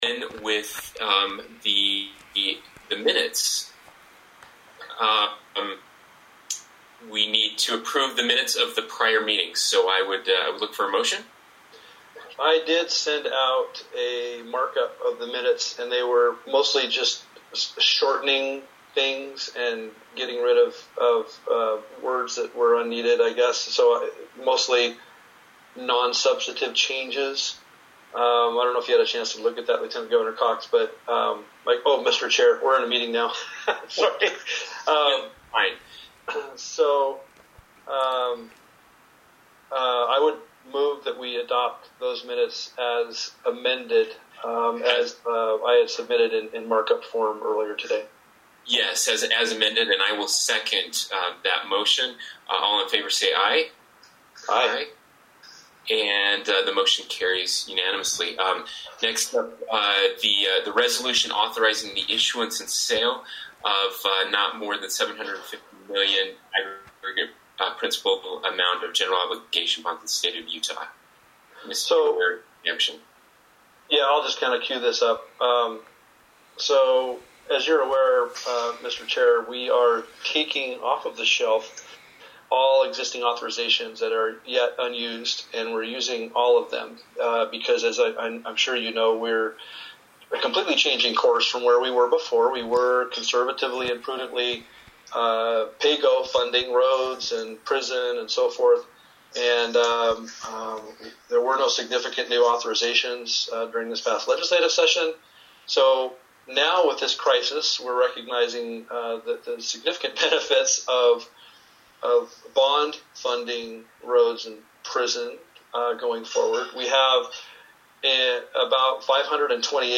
One or more Members of the Commission may participate via telephonic conference originated by the Chair, and the meeting shall be an electronic meeting, and the anchor location shall be the offices of the State Treasurer set forth above, within the meanings accorded by Utah law.
Telephonic Meeting Only